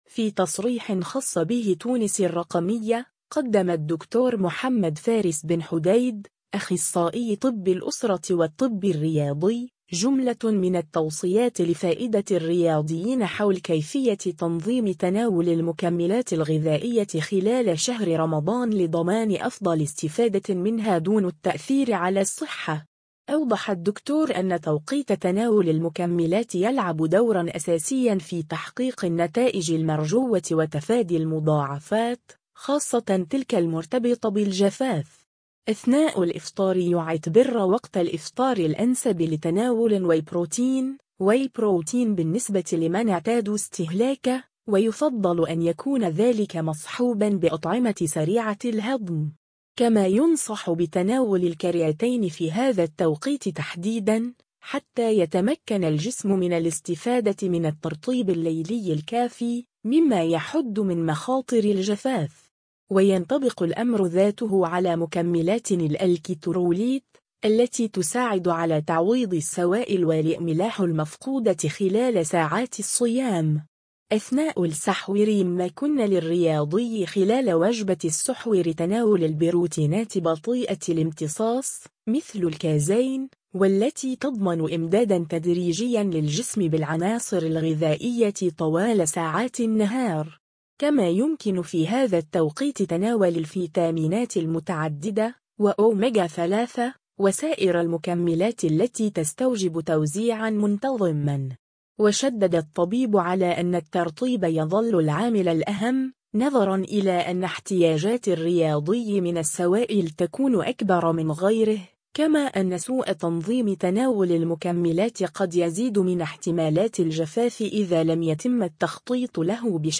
متى يجب تناول المكملات الغذائية أثناء رمضان؟ نصائح أخصائي في الطب الرياضي [فيديو]
في تصريح خصّ به تونس الرقمية